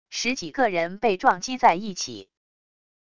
十几个人被撞击在一起wav音频